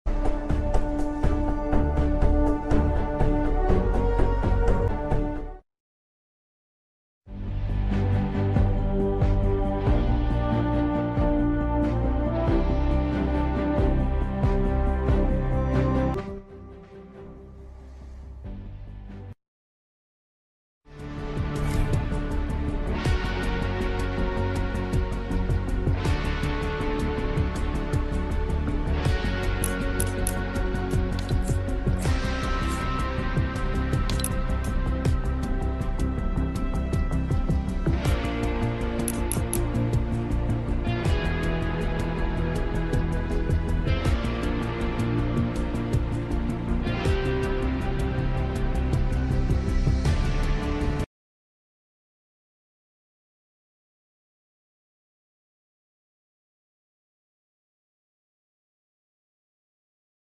loading in sound and lobby music